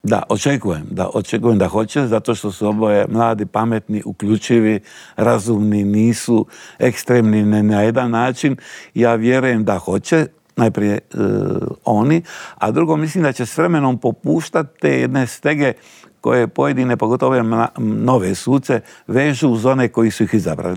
Stoga smo u Intervjuu tjedna Media servisa ugostili još uvijek aktualnog predsjednika tog državnog tijela, Miroslava Šeparovića.